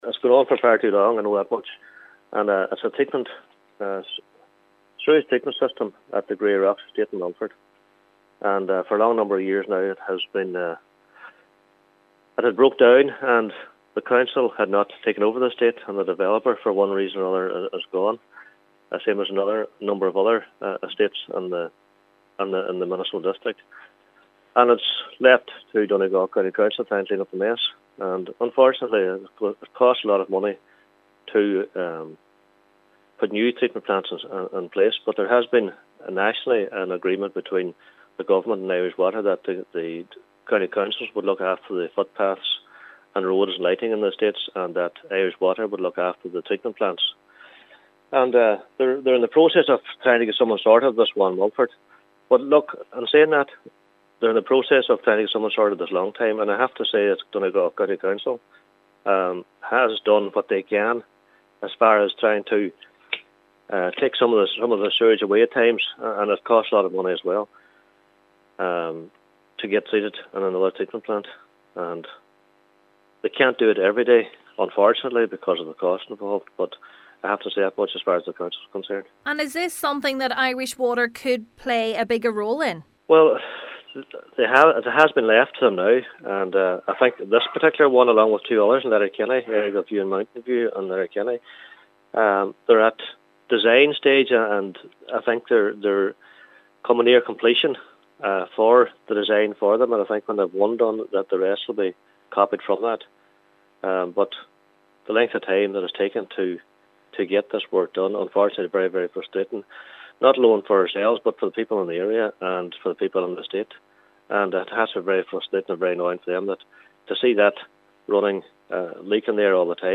Councillor Liam Blaney says efforts have been ongoing to address the issue for far too long.